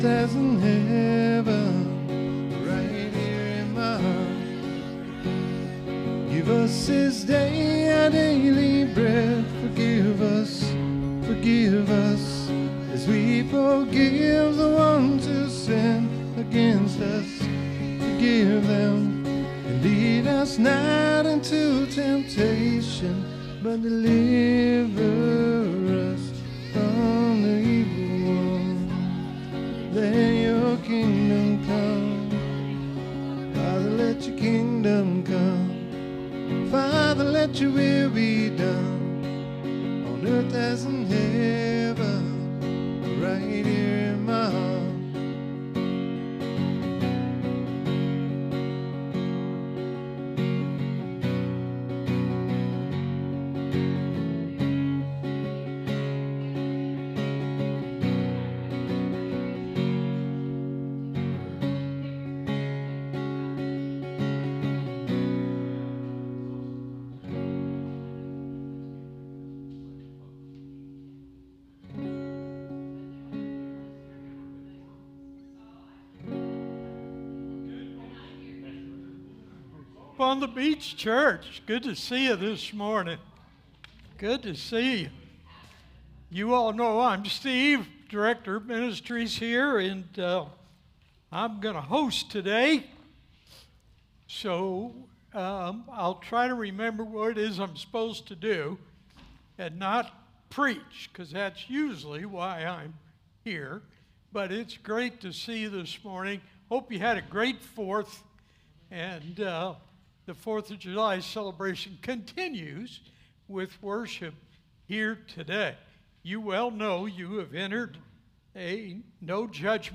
SERMON DESCRIPTION We gather today on this holiday weekend to honor God and thank Him for all He has provided.